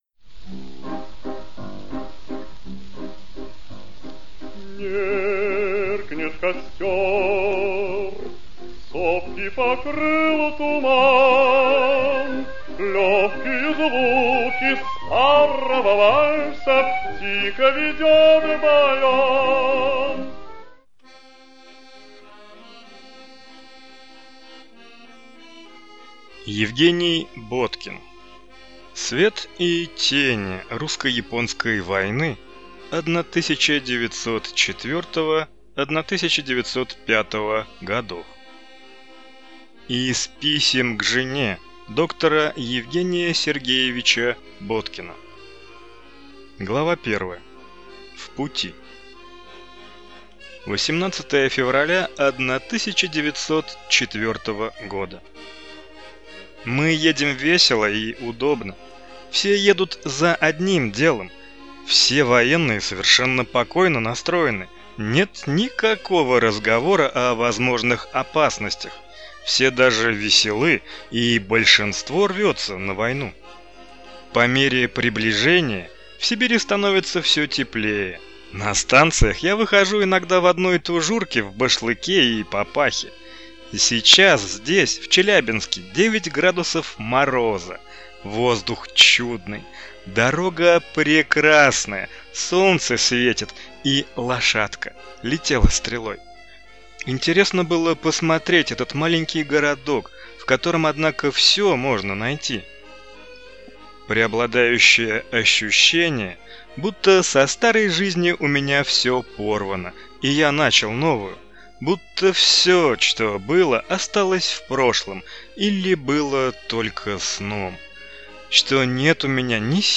Аудиокнига Свет и тени русско-японской войны 1904-5 гг.